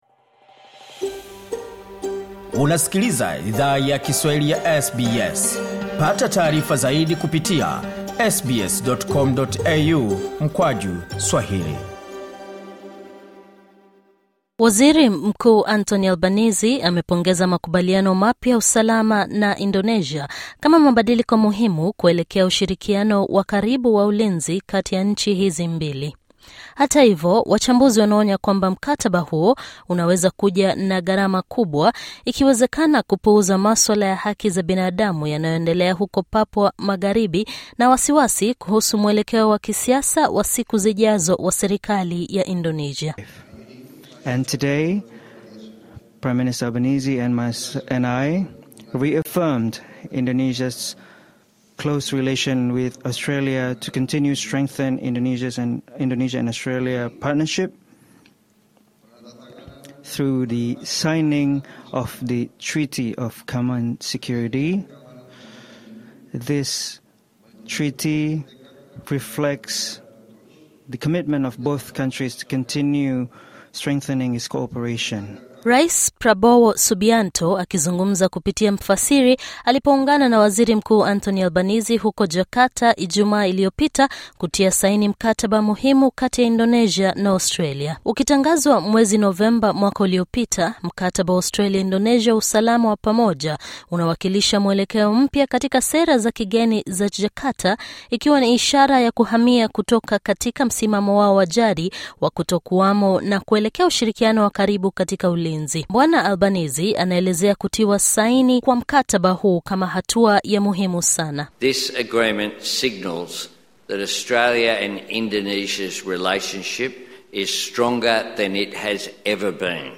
Rais Prabowo Subianto akizungumza kupitia mfasiri - alipoungana na Waziri Mkuu Anthony Albanese huko Jakarta (Ijumaa) kutia saini mkataba muhimu kati ya Indonesia na Australia.